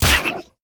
spitter-death-1.ogg